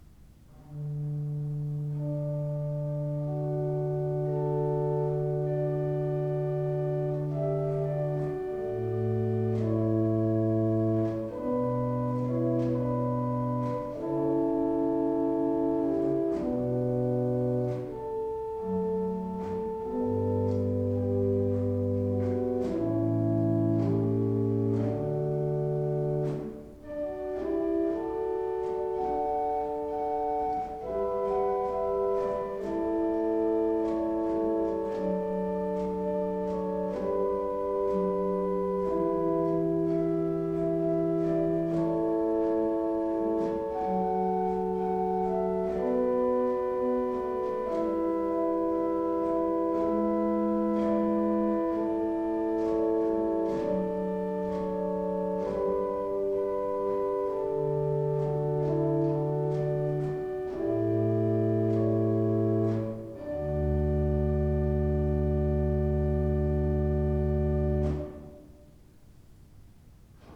1787 Tannenberg Organ
To listen to an improvisation on the Flauto Amabile 8', click
Improvisation_Flaut_Amabile_8F.wav